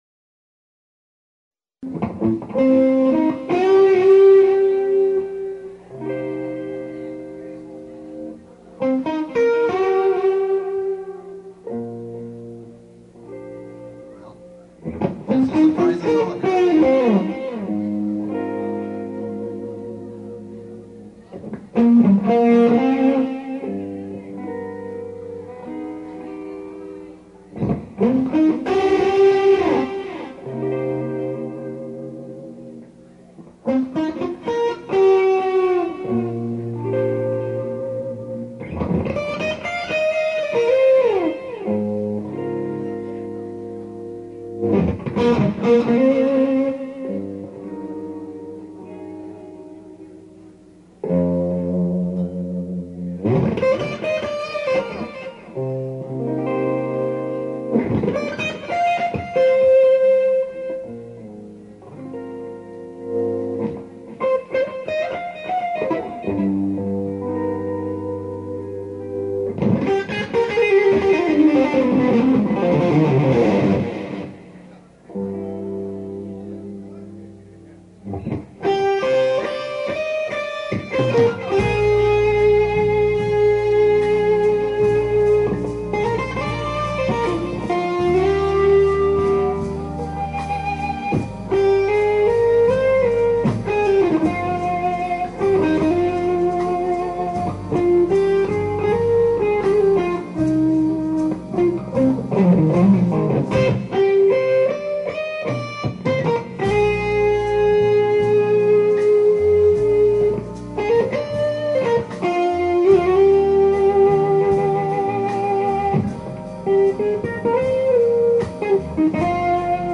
live, bootleg homage